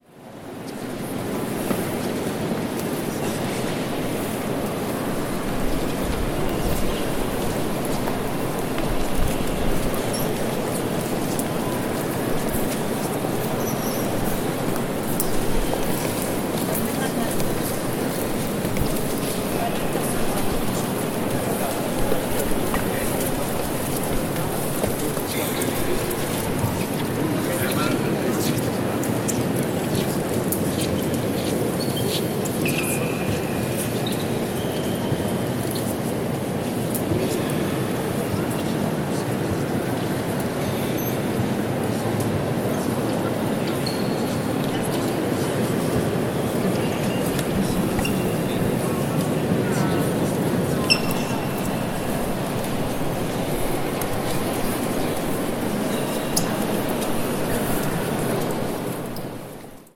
Inside the Duomo, Florence
Squeaking shoes, shuffling feet and the reverence of thousands of tourists.